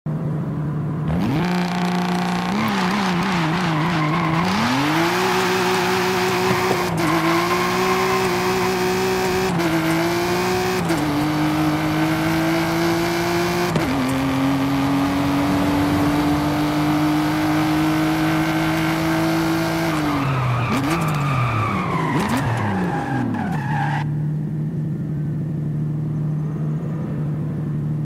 1998 TVR Cerbera Speed 12 sound effects free download
1998 TVR Cerbera Speed 12 Launch Control & Sound - Forza Horizon 5